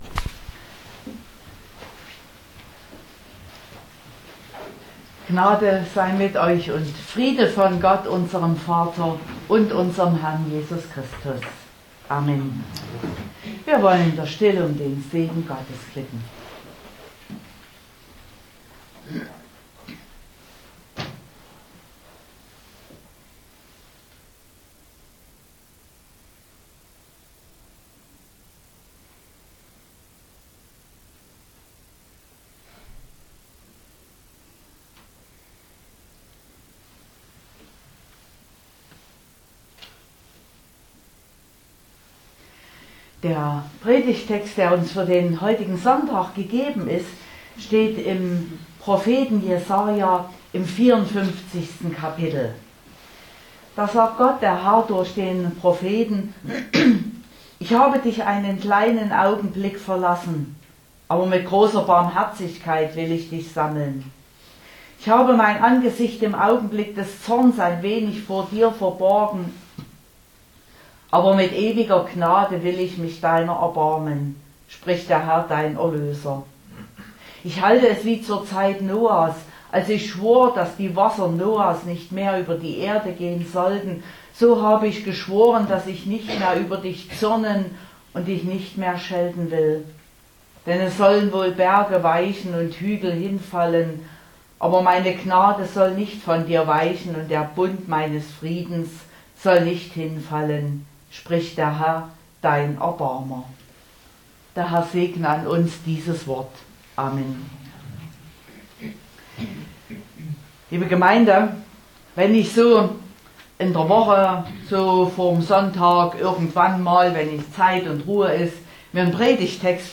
19.03.2023 – Gottesdienst
Predigt (Audio): 2023-03-19_Was_willst_du_uns_sagen_Herr_.mp3 (28,5 MB)